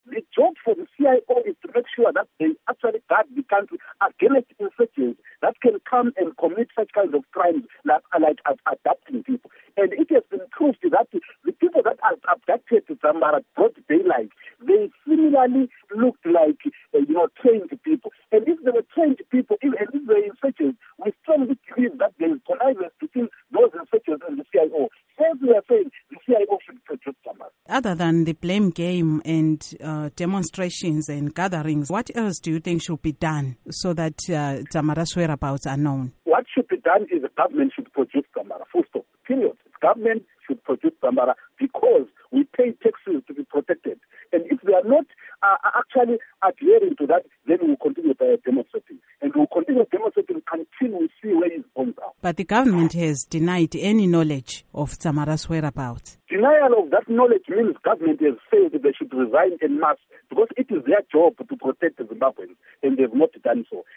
Interview with Abednico Bhebhe